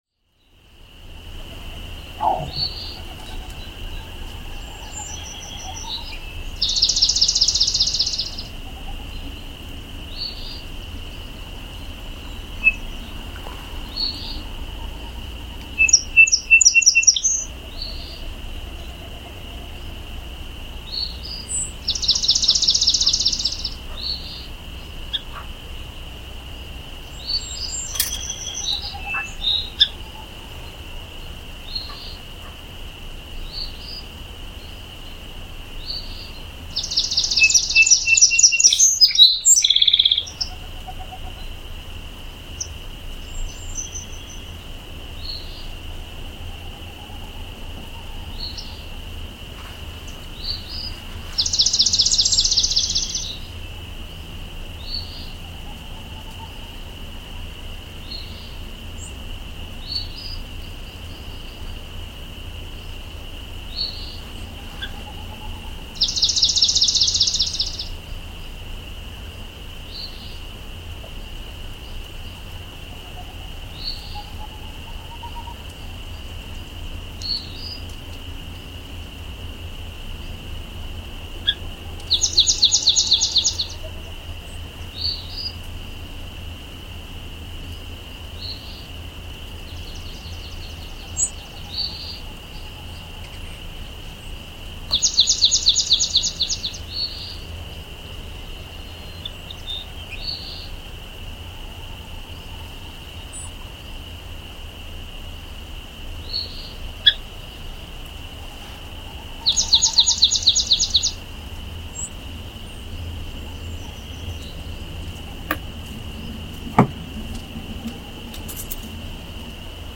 Birdsong at St. John River
Birdsong in a quiet rural spot on St. John River in New Brunswick, Canada.
According to Birdnet, the species you can hear include the swamp sparrow, eastern Phoebe and Wilson's snipe.